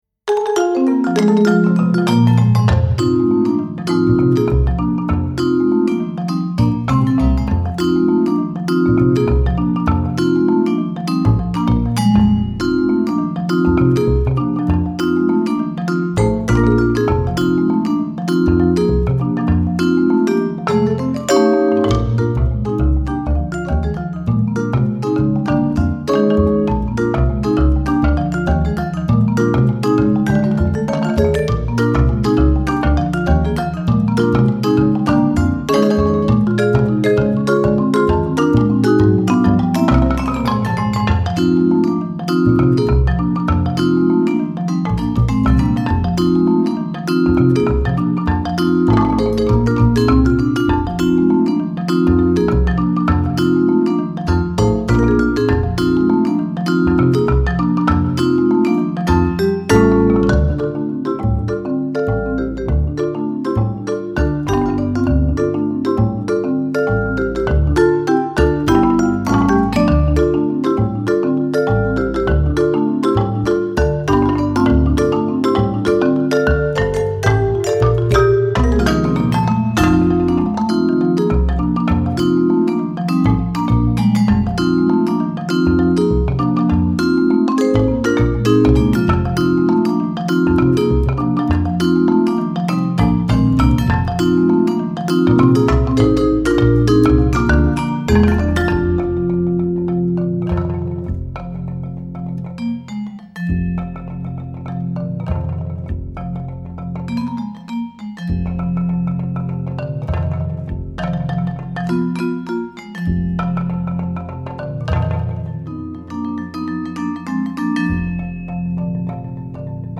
Voicing: Percussion Quintet